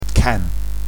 canplein.mp3